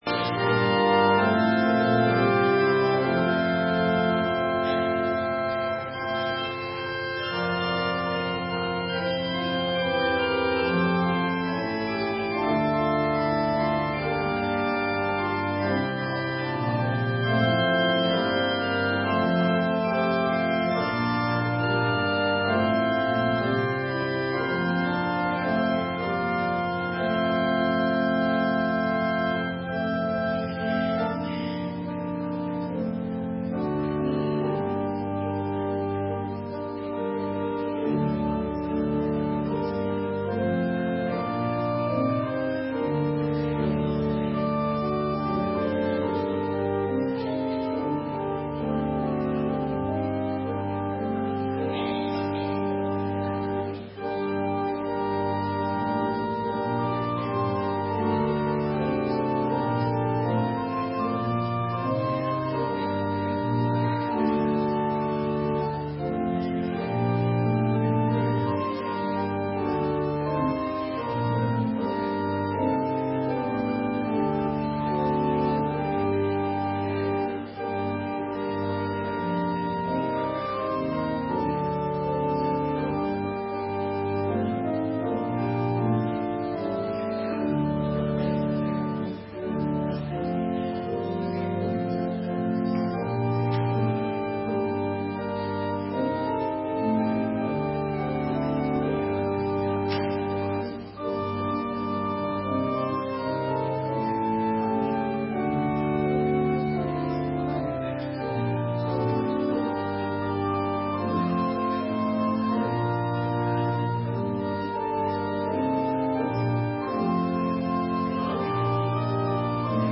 Opgenomen kerkdiensten